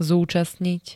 Zvukové nahrávky niektorých slov
oajb-zucastnit.ogg